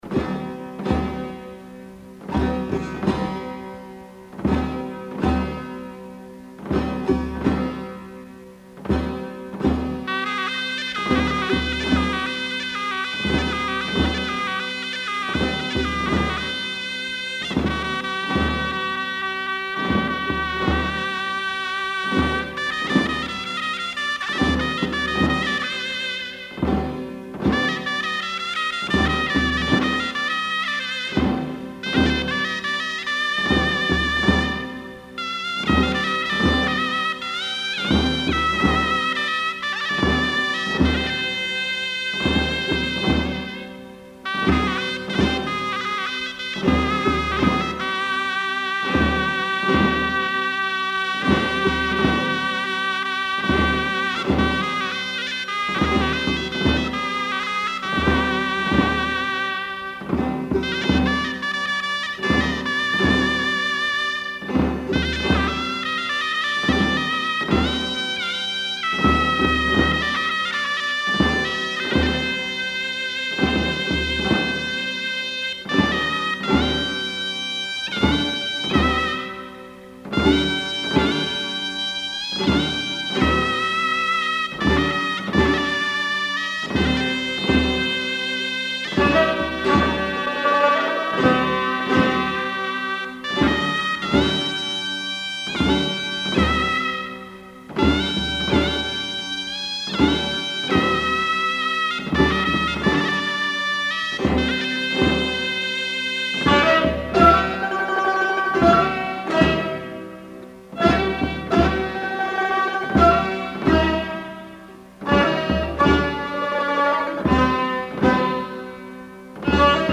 آهنگ لری
(ساریخوانی)
Lor music
سرنا
کمانچه لری